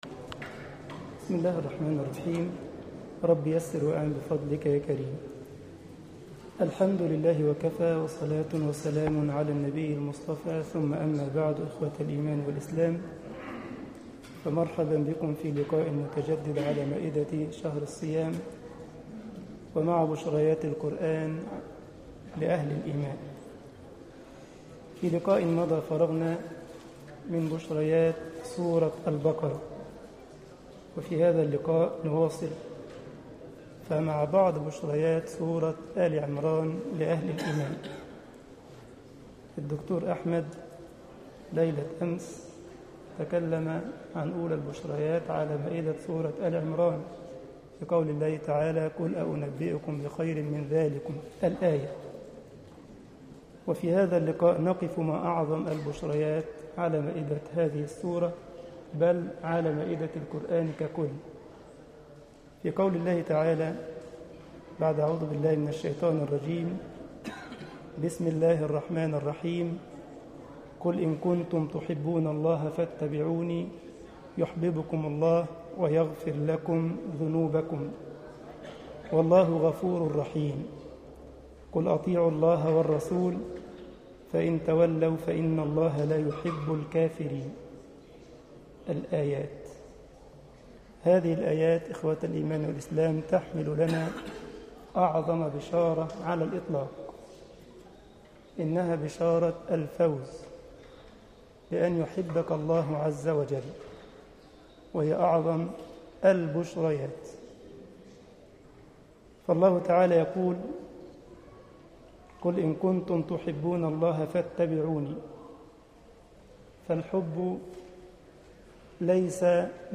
مسجد الجمعية الإسلامية بالسارلند ـ ألمانيا درس 13 رمضان 1433 هـ